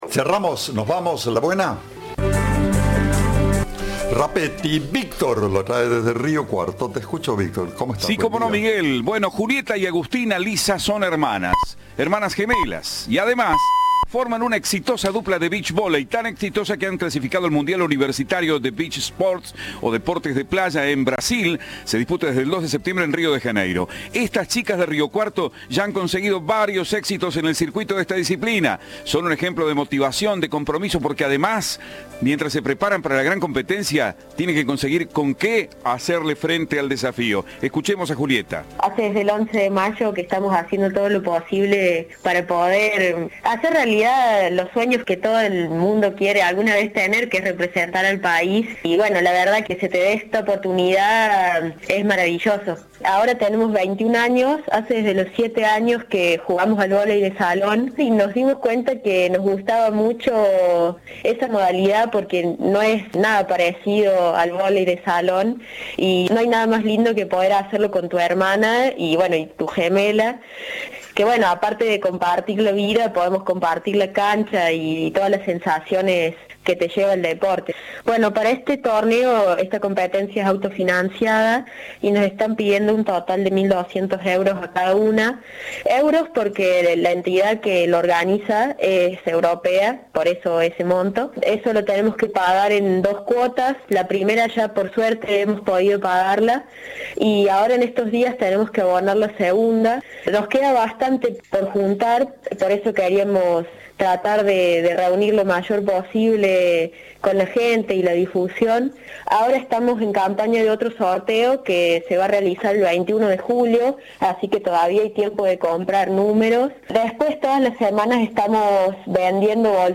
en diálogo con Cadena 3